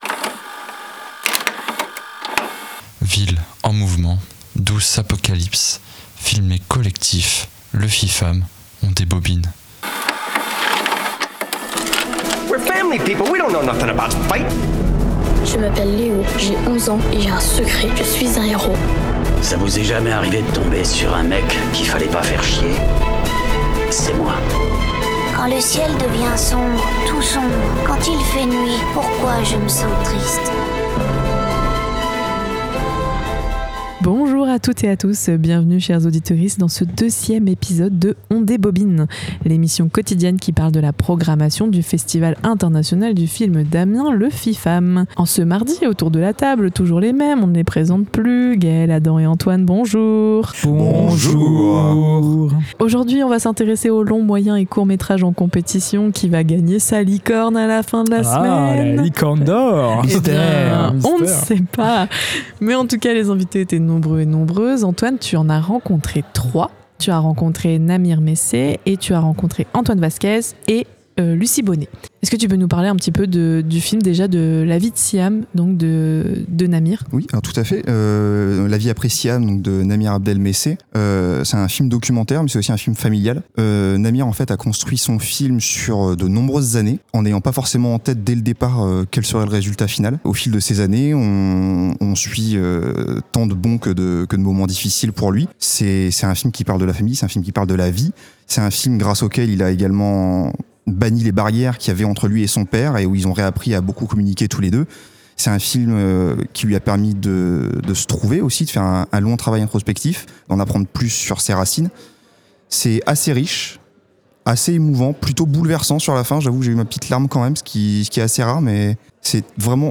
avec des interviews, des debriefs et d’autres petites surprises.